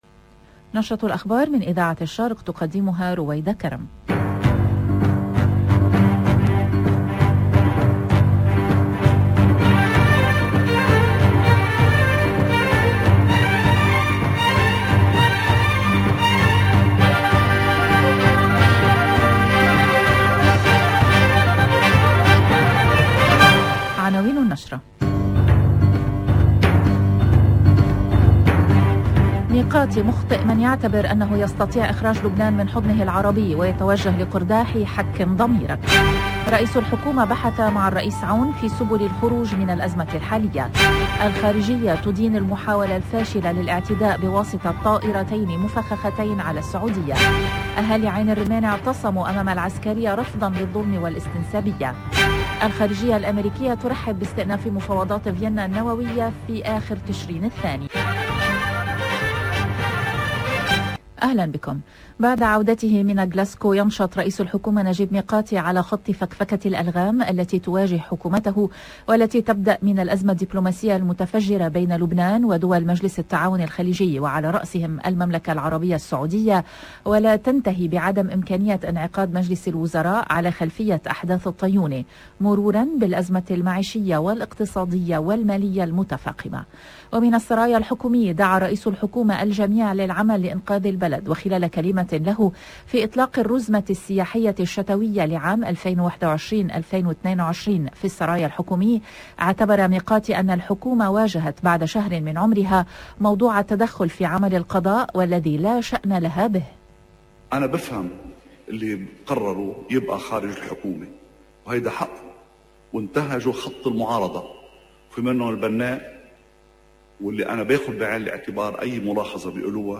LE JOURNAL DU LIBAN EN LANGUE ARABE DU 4/11/2021 DE 13H30